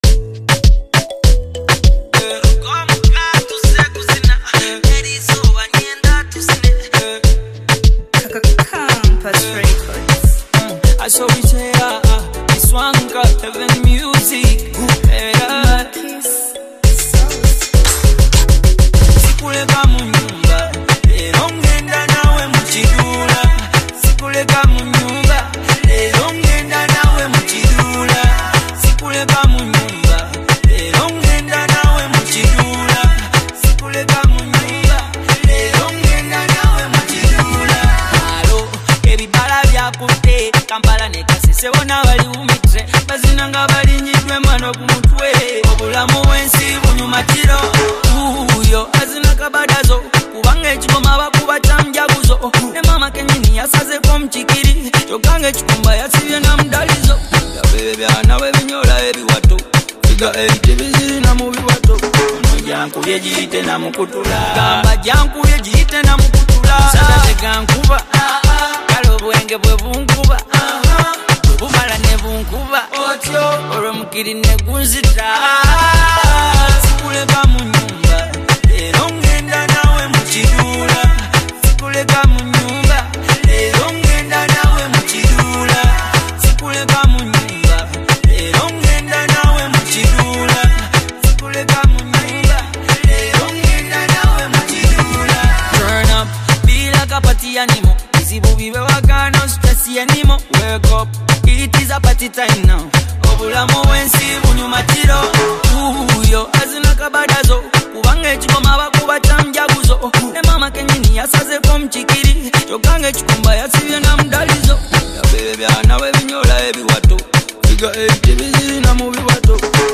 powerful, emotive vocals create a mesmerizing soundscape